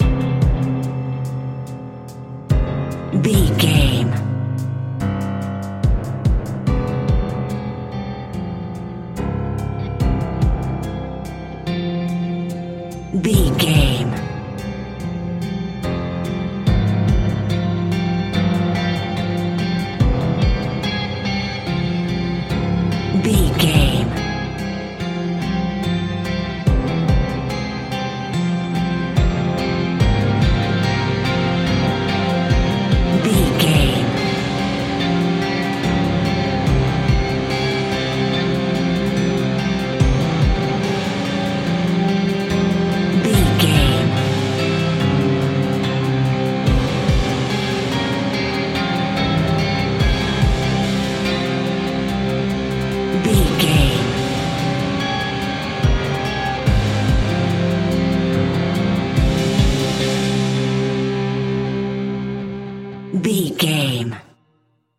Fast paced
Thriller
Ionian/Major
F♯
dark ambient
synths